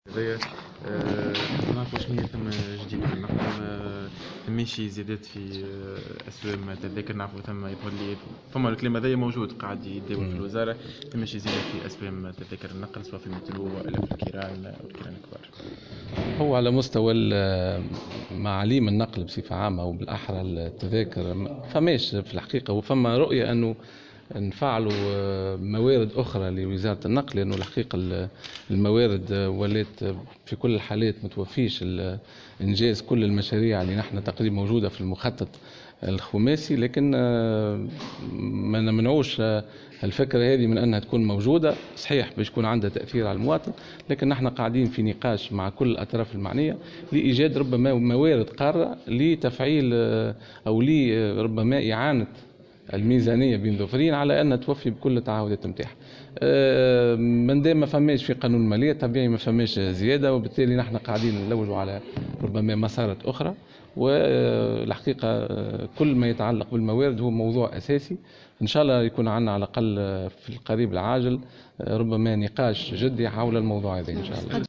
أكد وزير النقل رضوان عيارة في تصريح لمراسل الجوهرة "اف ام" اليوم الخميس23 نوفمبر 2017 أن لا زيادة في أسعار تذاكر النقل سواء في المترو أو الحافلات خلال السنة المقبلة لأن هذه الزيادات لم ترد في قانون المالية لسنة 2018.